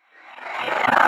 VEC3 Reverse FX
VEC3 FX Reverse 11.wav